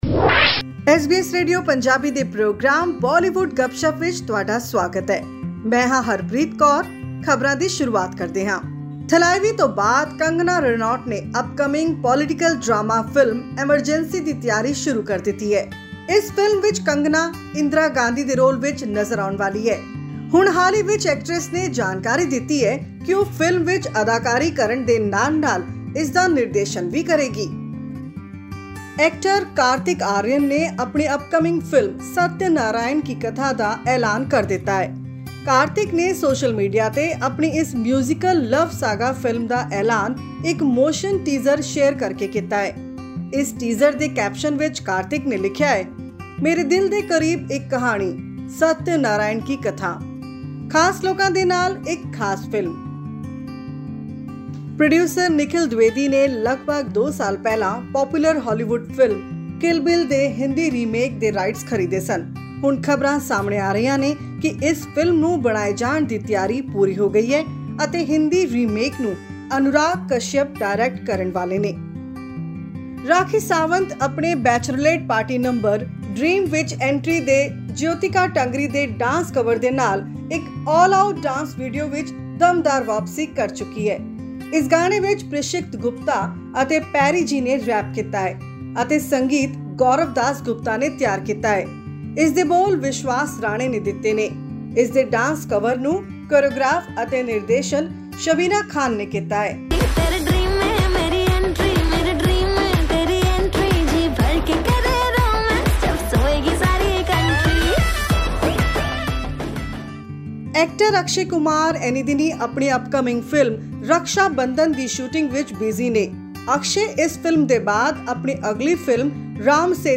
Bollywood actor Kangana Ranaut has announced that she will be directing her upcoming project titled 'Emergency', based on former Prime Minister Indira Gandhi’s life. This and more in our weekly bulletin from the world of cinema and music.